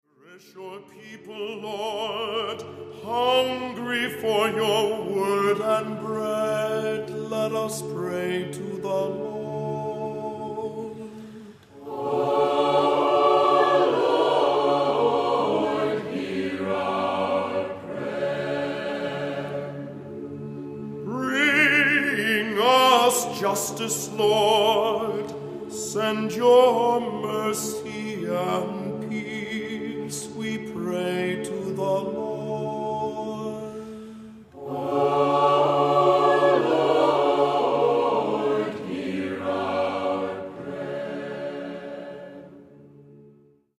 Voicing: SATB; Cantor; Priest; Assembly